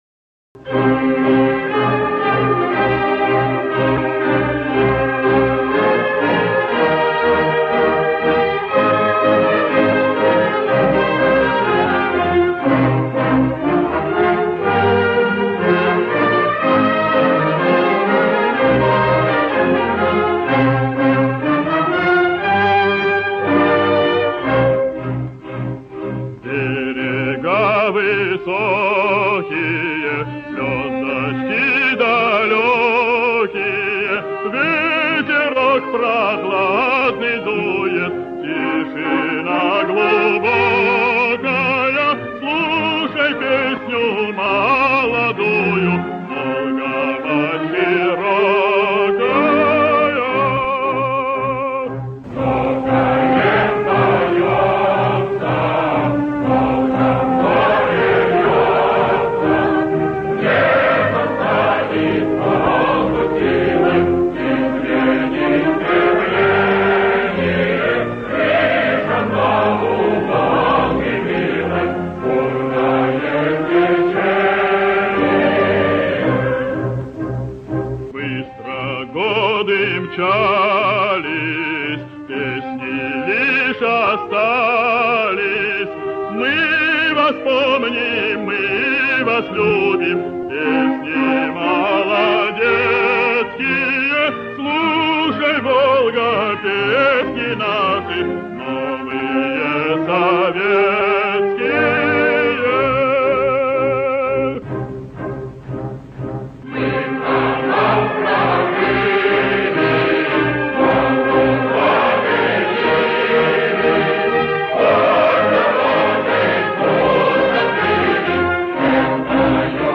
хор и оркестр Исполнение 1937г.